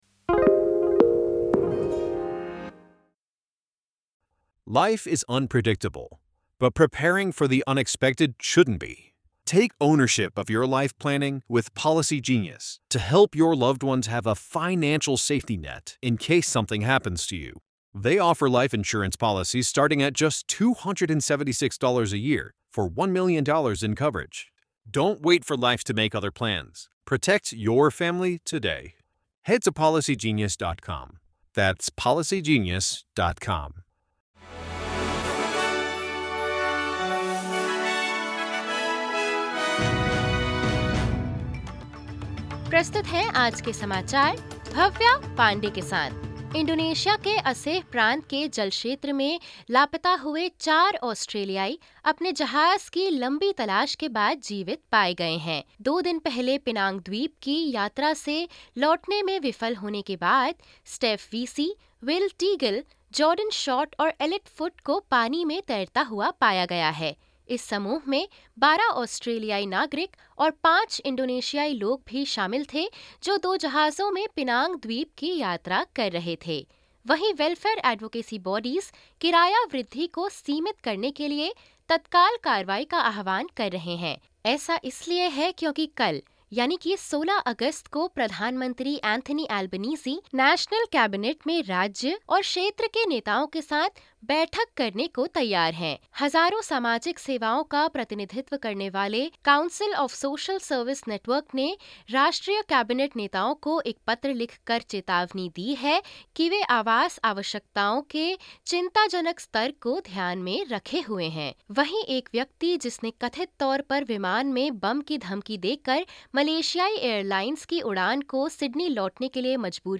SBS Hindi News